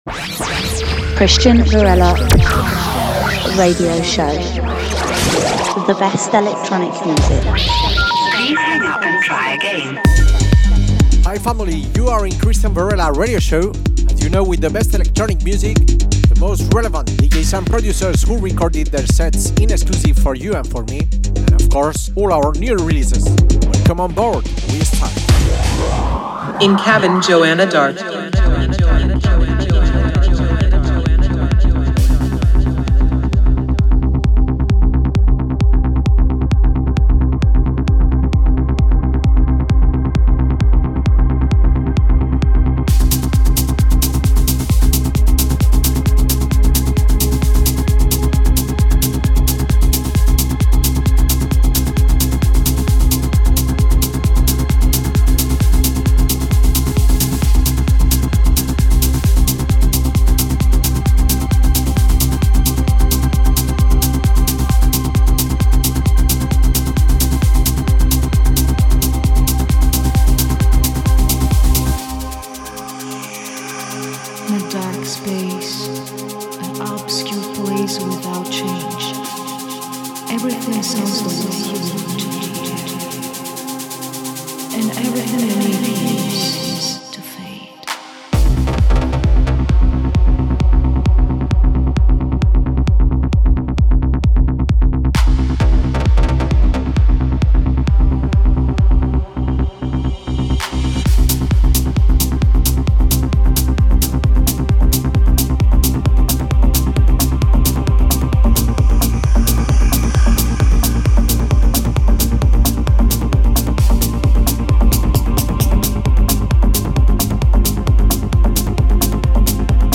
Techno Music